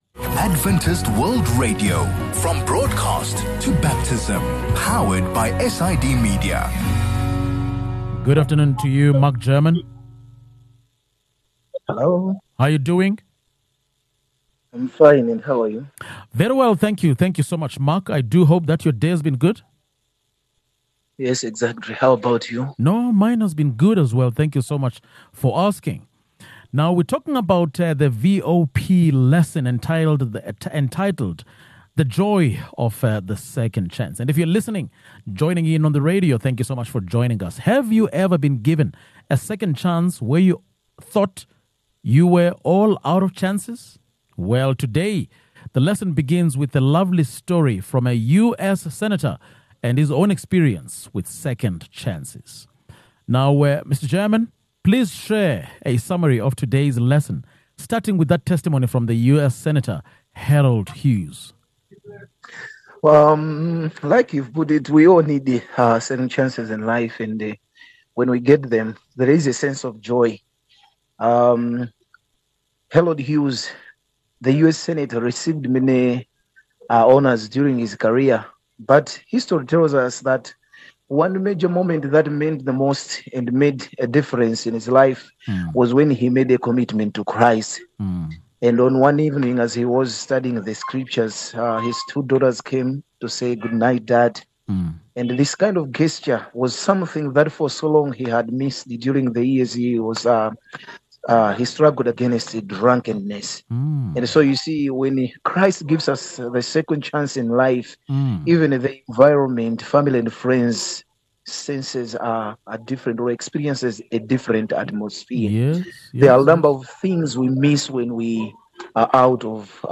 23 Feb Bible Corner | VOP Lesson: The Joy of the Second Chance 10 MIN Download (4.8 MB) Have you ever been given a second chance when you thought you were all out of chances?